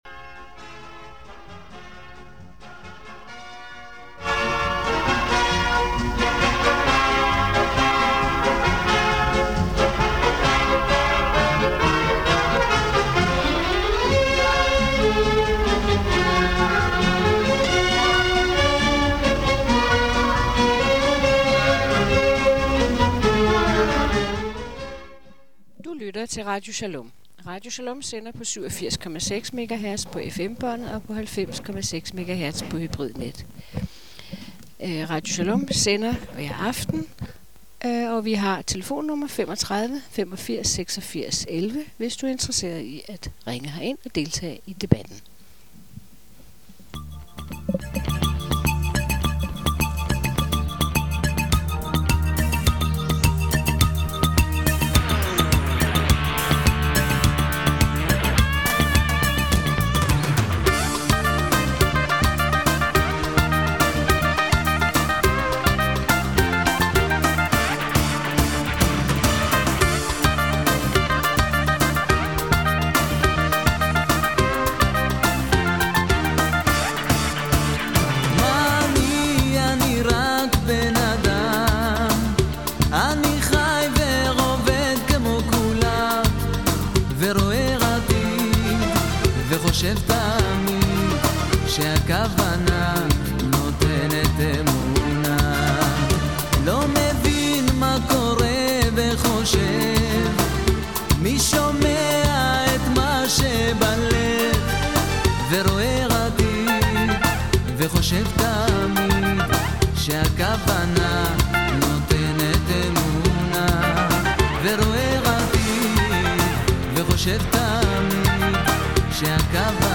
interview om “Senior aktivisterne”
Udgivet i Interviews